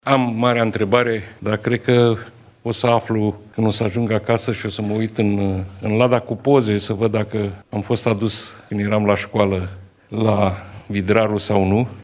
Pentru că nu și-a amintit în fața presei, șeful Guvernului a spus că va verifica dacă în timpul școlii a fost adus în excursie la Barajul Vidraru sau nu.